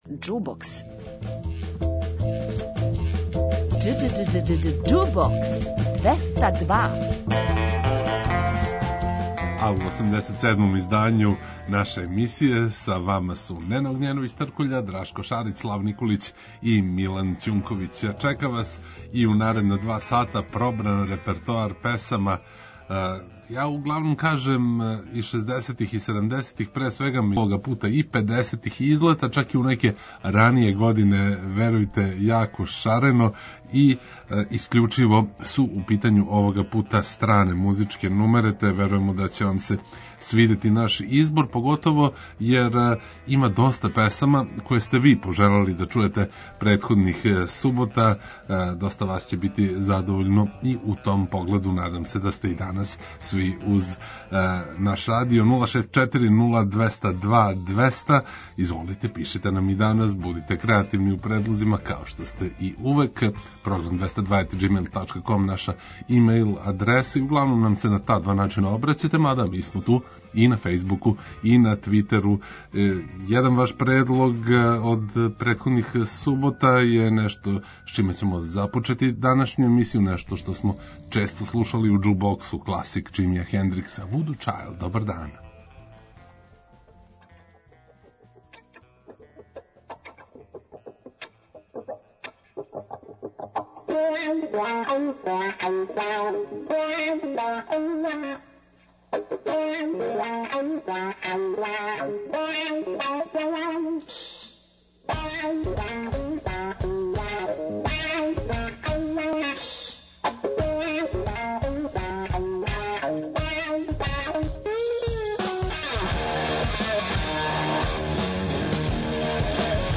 У 87. издању емисије која слави старе, добре, непролазне хитове, поново ћемо направити вама, надам се, пријатан микс песама које сте ви пожелели да чујете претходних субота и оних које смо ми сами одабрали.